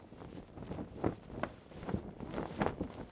cloth_sail8.L.wav